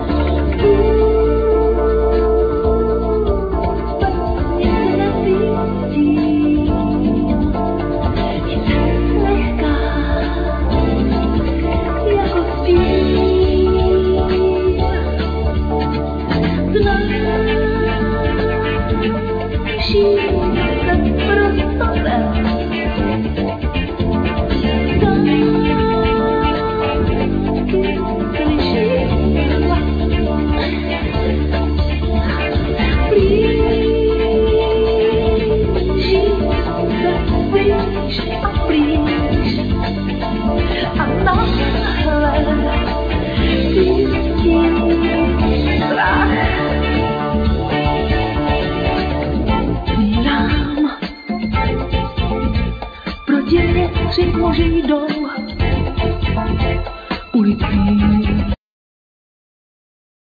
Keyboards, Fender piano
Sopranosax, Violin
Bass
Percussions
Drums
Vocal
Acoustic guitar
Guitar